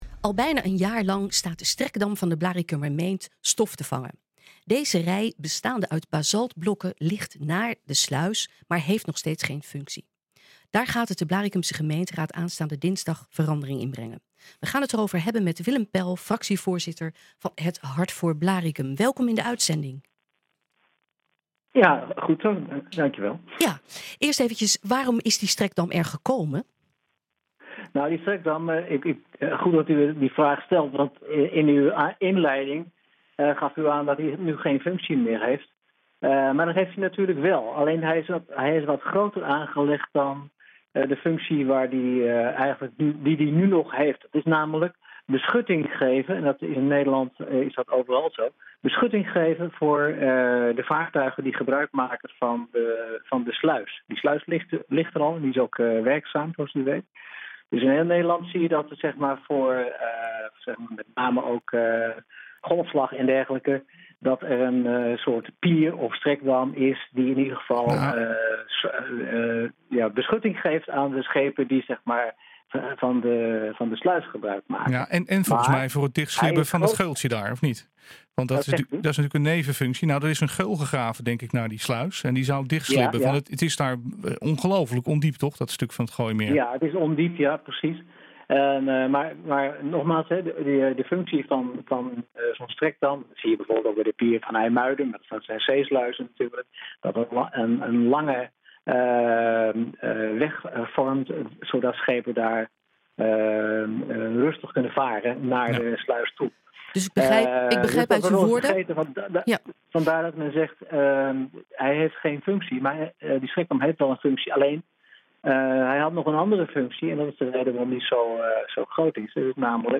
Daar gaat het de Blaricumse gemeenteraad aanstaande dinsdag verandering in brengen. We gaan het erover hebben met Willem Pel, fractievoorzitter van het Hart voor Blaricum.